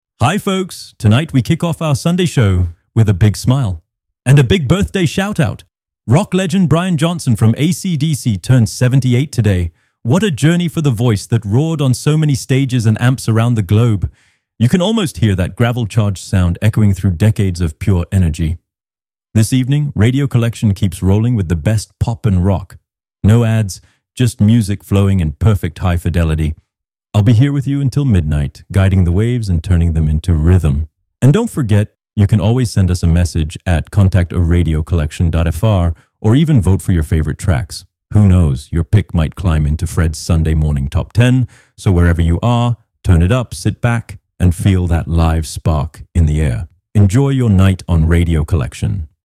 3. Pop rock birthdays